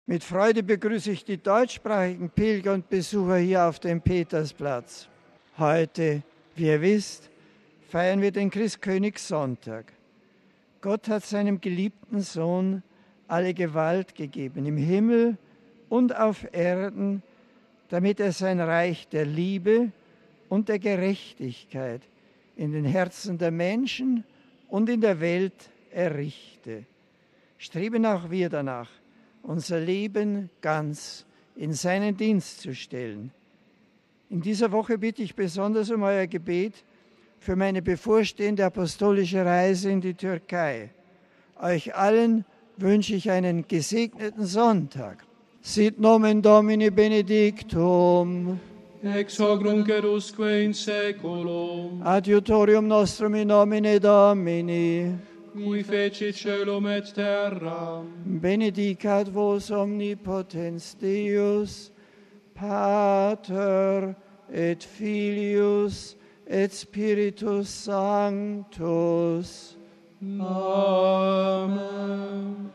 MP3 Gott ist Liebe und Wahrheit, und sowohl die Liebe als auch die Wahrheit zwingen sich nicht auf, sondern klopfen an die Herzen der Menschen an, um in Frieden und Freude aufgenommen zu werden. Das sagte Papst Benedikt XVI. beim Angelusgebet zum heutigen Christkönigsfest.